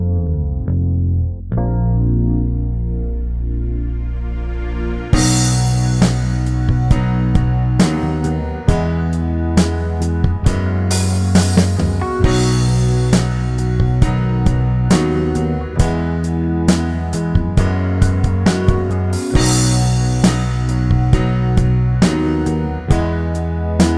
Duet Version Pop (1970s) 6:12 Buy £1.50